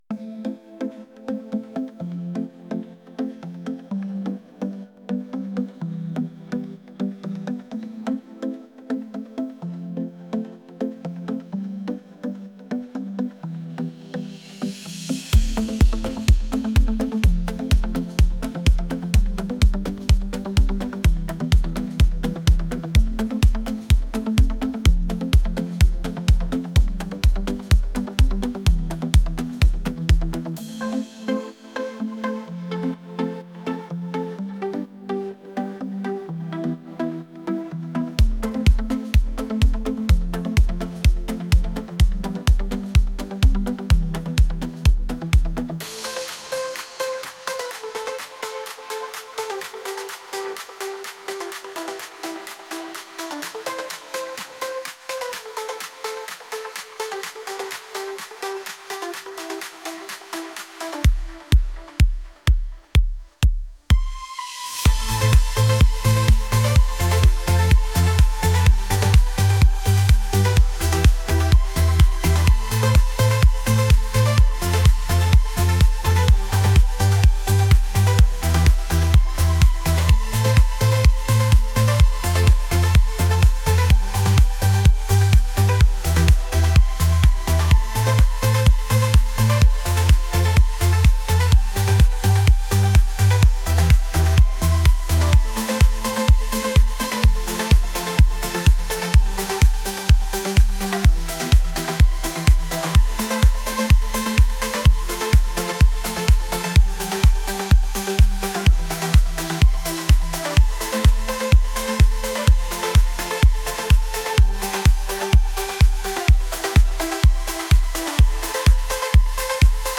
romantic | pop